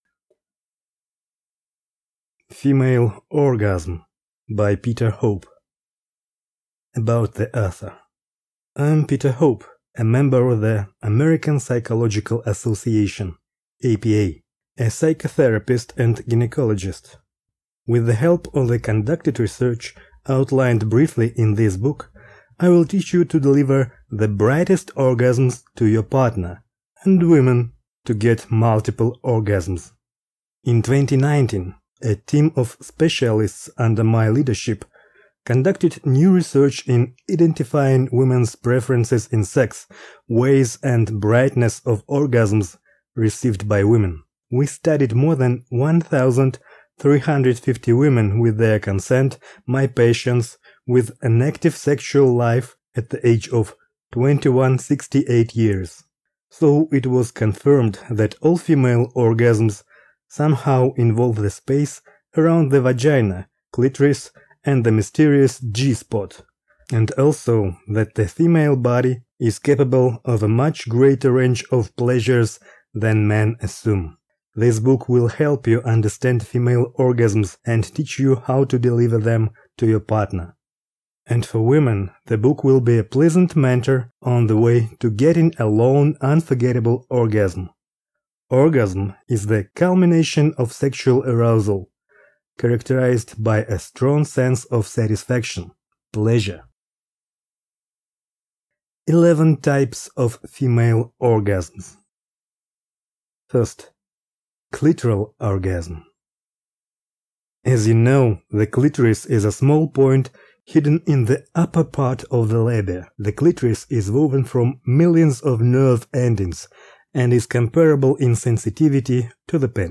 Аудиокнига Female orgasm | Библиотека аудиокниг